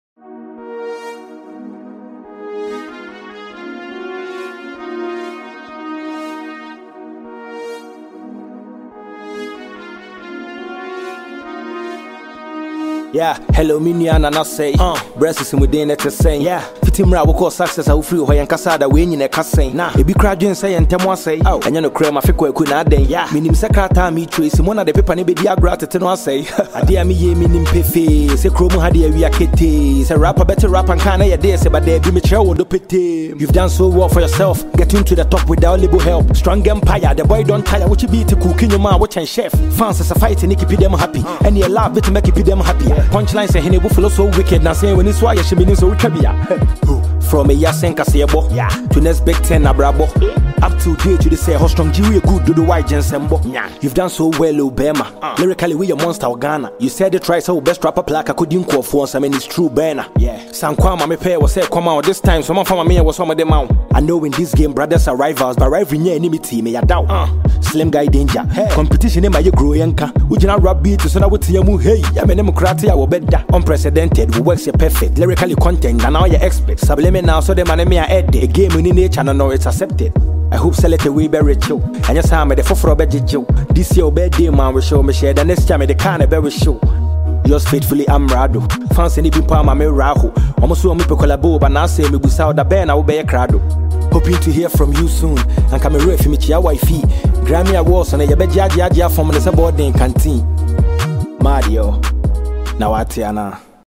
Award-winning Ghanaian rapper and songwriter
smooth freestyle